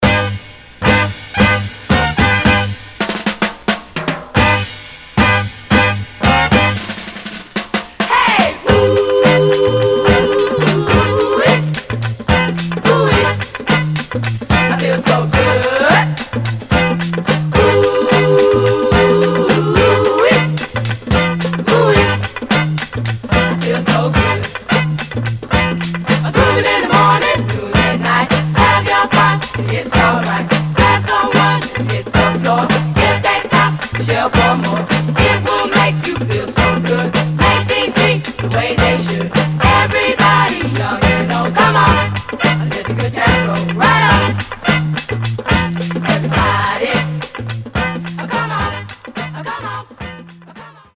Some surface noise/wear
Mono
Funk